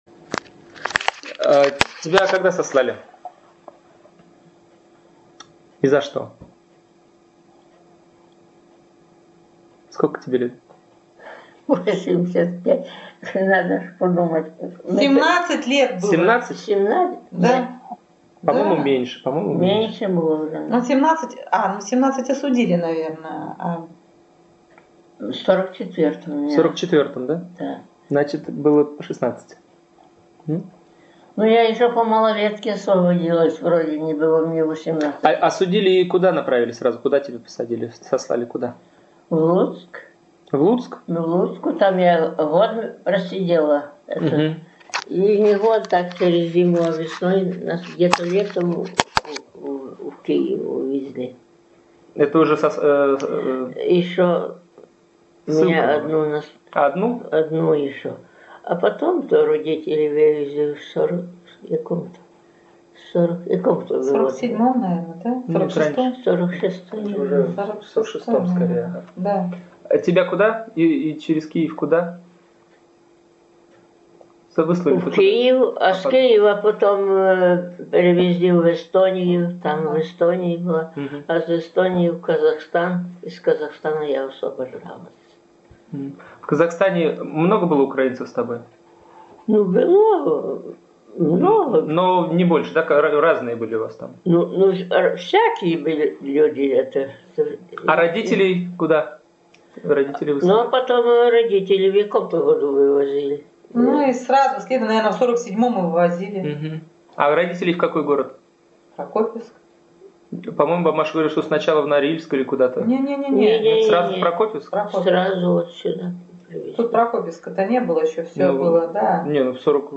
Он предоставил нам записи интервью с людьми, пережившими трагедию раскулачивания. Мы предлагаем вашему вниманию его полевые материалы. Несмотря на то, что время от времени они отрывочны, и качество записи невысокое, информация, которую содержат рассказы респондентов, представляет интерес для исследователей истории России ХХ века.